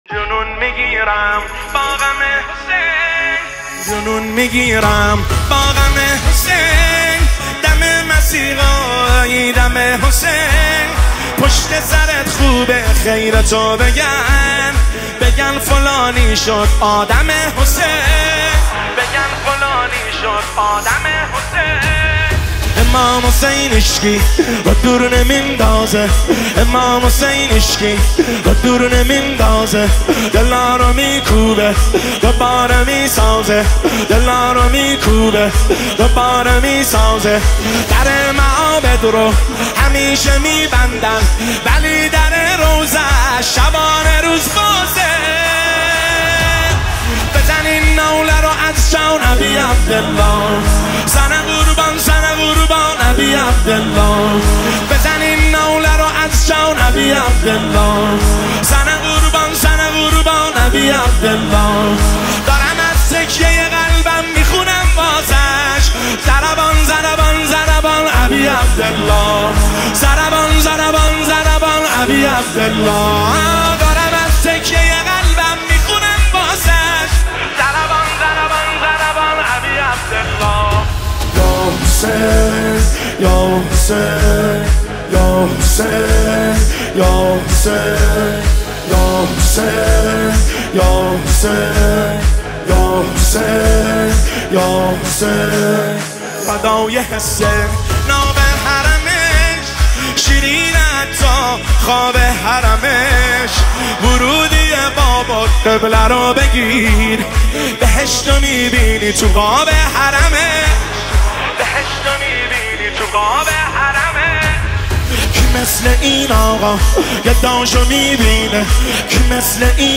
زمینه شب شهادت امام حسن عسکری (ع) 1404
هیئت رزمندگان غرب تهران